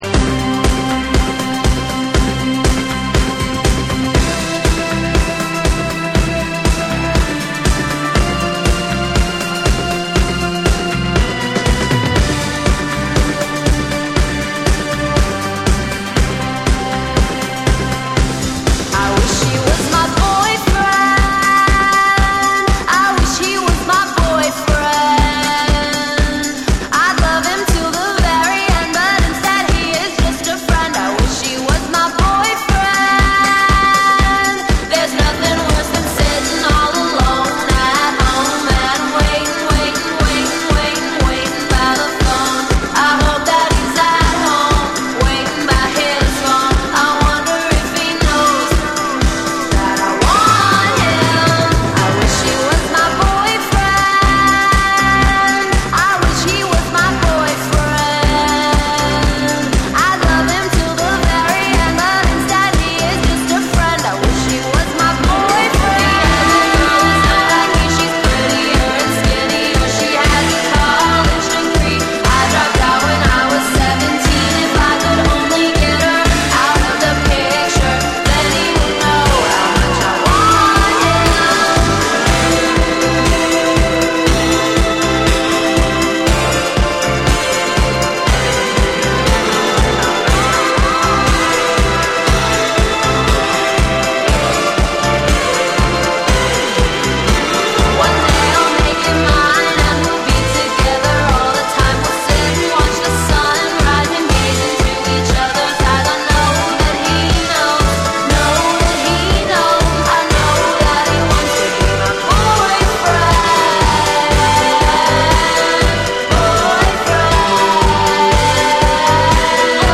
オリジナルの甘く爽やかなメロディを生かしつつ、ダンスフロアでも映える1枚！
TECHNO & HOUSE / NEW WAVE & ROCK